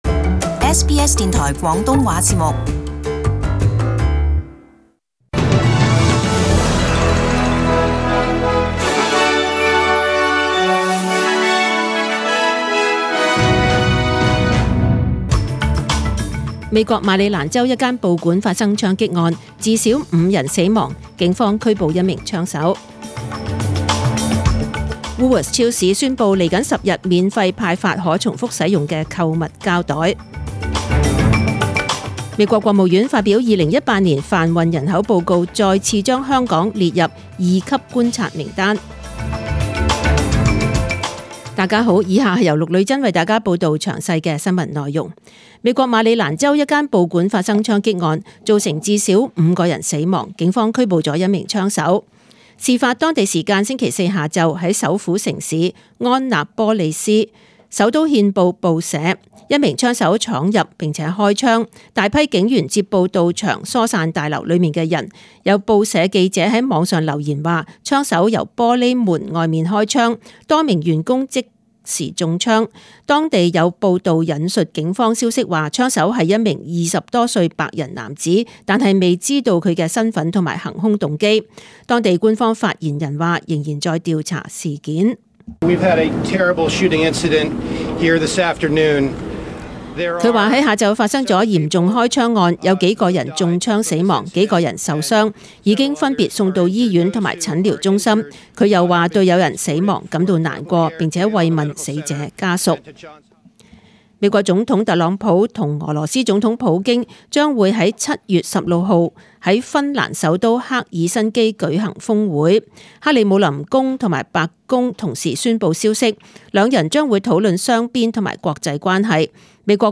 SBS中文新聞 （六月二十九日）